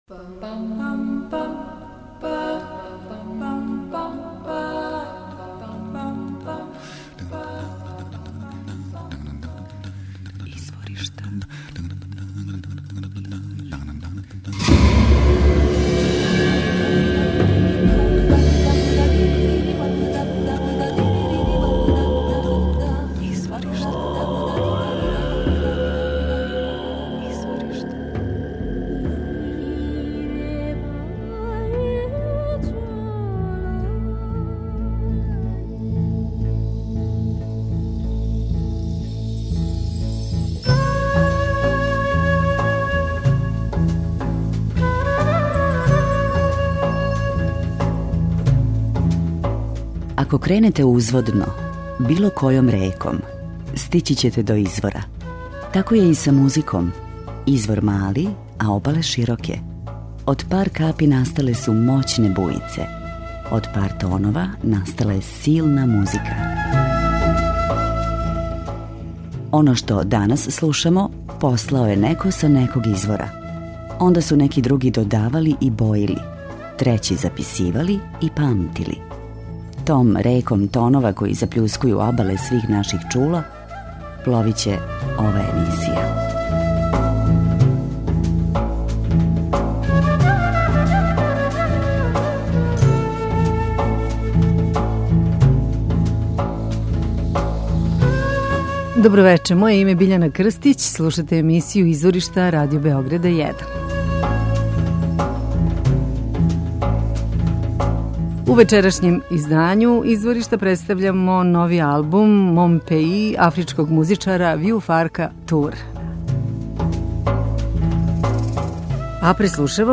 пијанистом и певачем